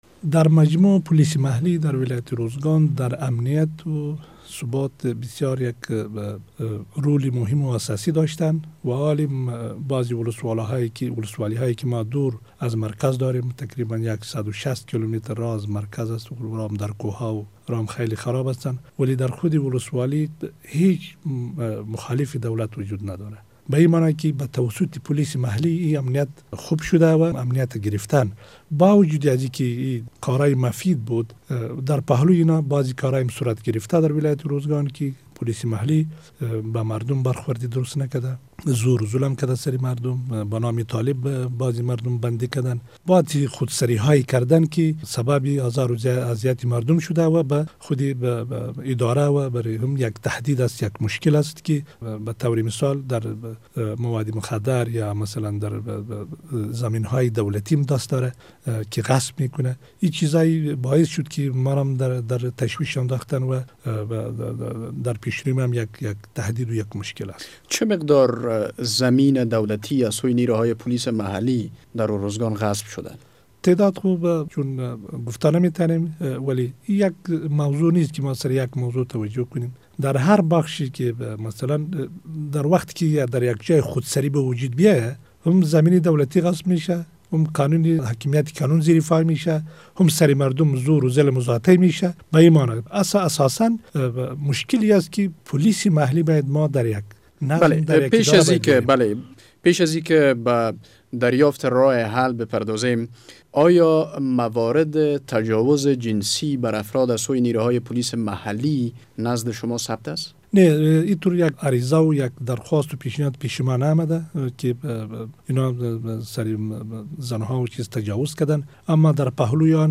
مصاحبه با والی ارزگان در مورد فعالیت های خودسرانهء پولیس محلی